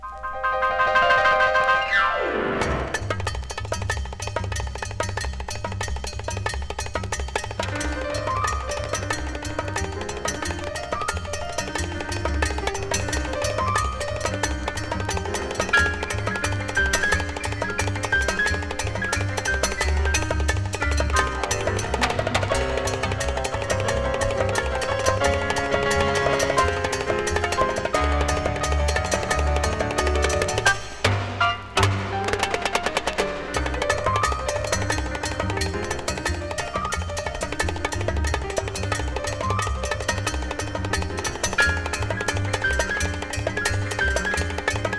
ジャケ裏写真のアルミニウム・ドーム録音で、スモールコンボエキゾな深く広がりあるサウンドは魅力的。